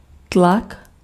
Ääntäminen
IPA : /ˈprɛʃə(ɹ)/